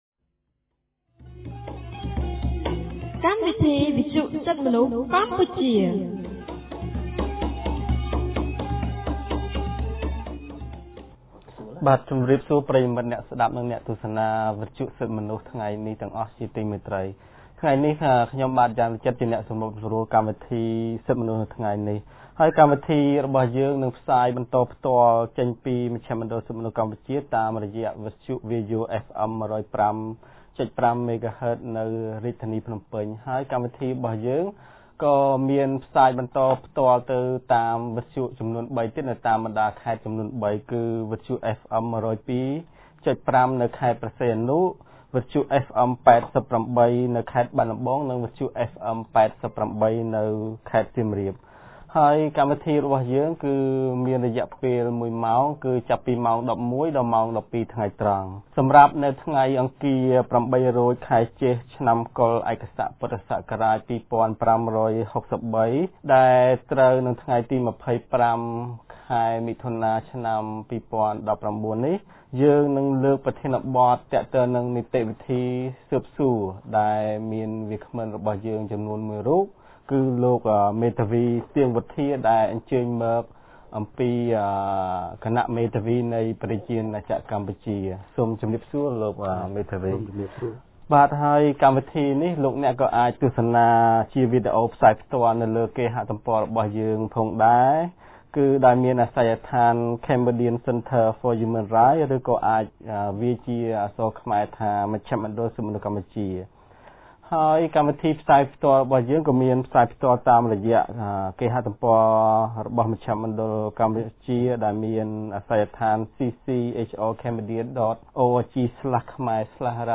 កាលថ្ងៃទី២៥ ខែមិថុនា ឆ្នាំ២០១៩ គម្រាងសិទ្ធិទទួលបានការជំនុំជម្រះដោយយុត្តិធម៌នៃ មជ្ឈមណ្ឌល សិទ្ធិមនុស្សកម្ពុជា បានរៀបចំកម្មវិធីវិទ្យុក្រោមប្រធានបទស្តីពី នីតិវិធីស៊ើបសួរ ។